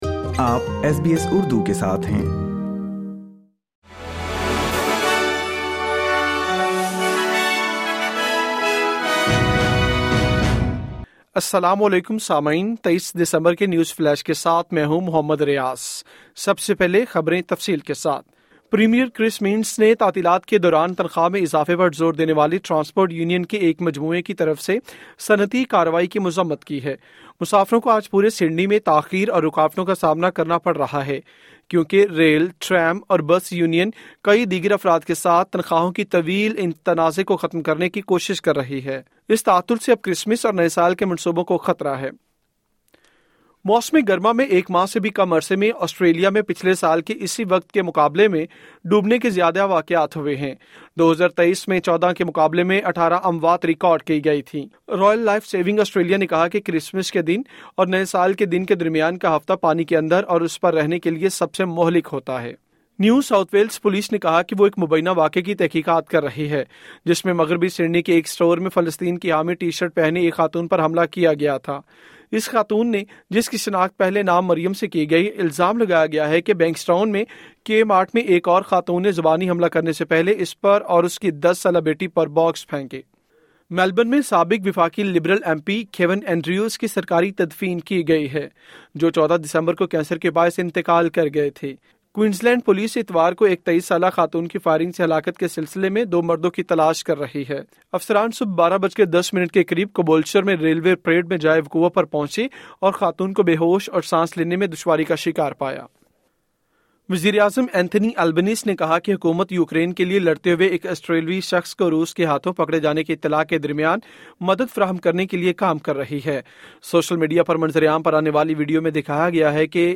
اردو نیوز فلیش: 23 دسمبر 2024